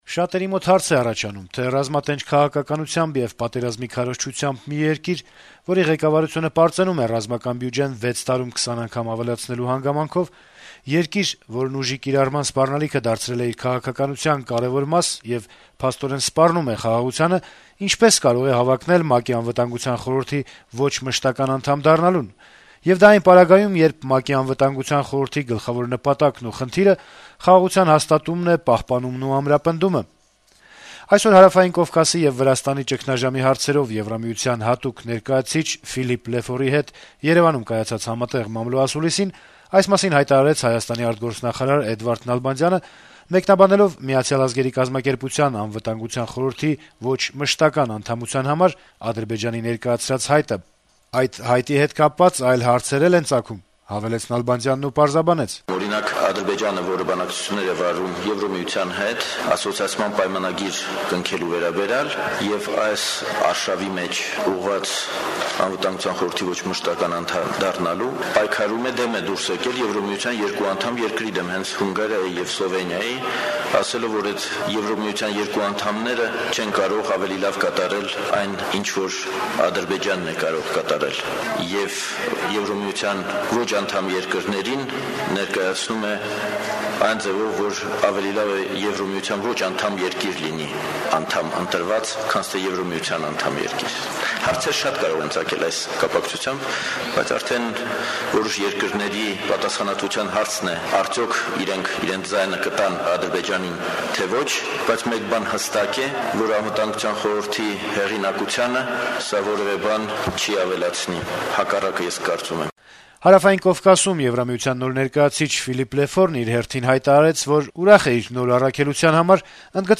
Հայաստանի արտգործնախարարի հետ հանդիպումից հետո երկու պաշտոնյաները հանդես եկան համատեղ մամլո ասուլիսով: